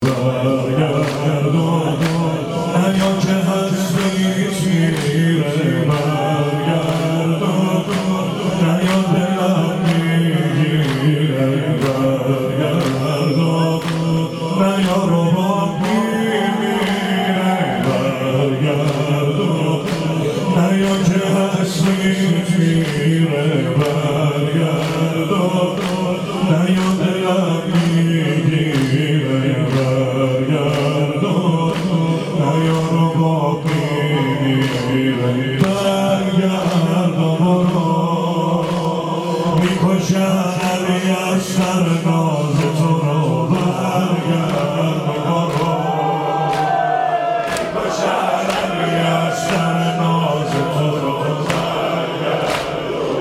زمینه | برگرد و نیا كه هستیت میره برگرد و نیا دلم میگیره
مداحی
در مراسم عزاداری حضرت علی اصغر(ع)